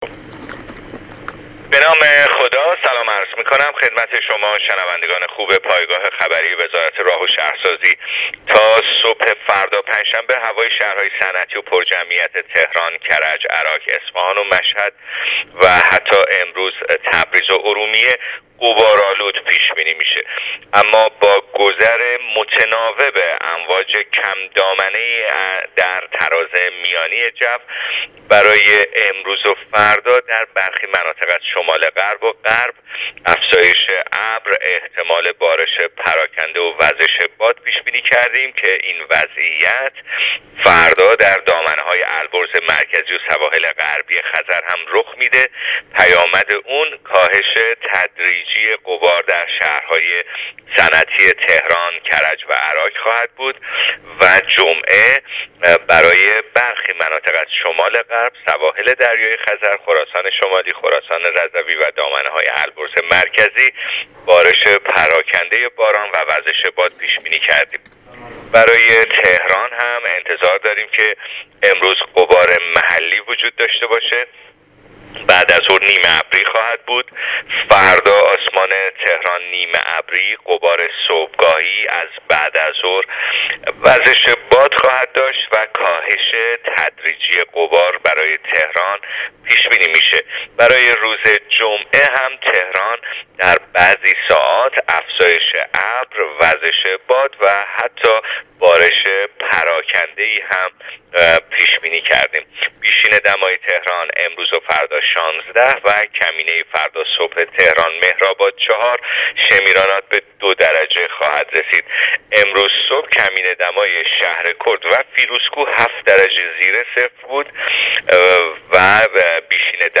دریافت فایل weather با حجم 2 MB برچسب‌ها: هواشناسی - گزارش رادیو تلویزیون